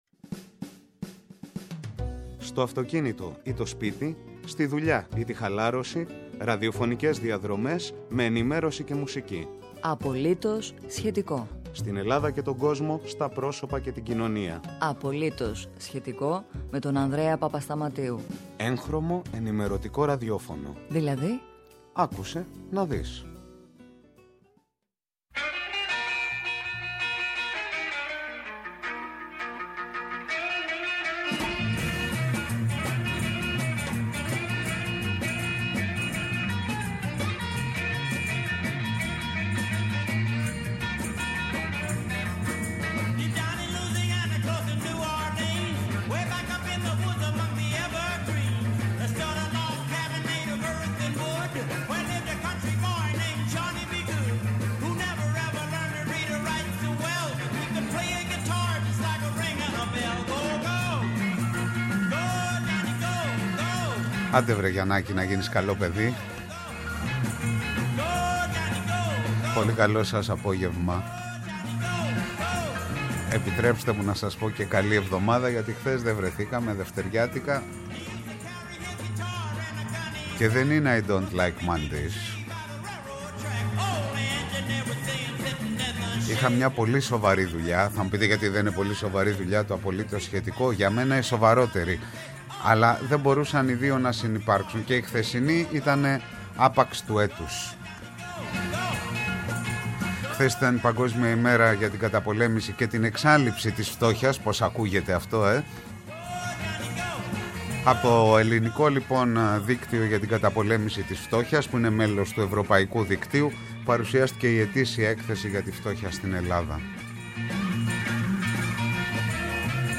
Απογευματινή ενημέρωση και ραδιόφωνο; «Απολύτως … σχετικό»!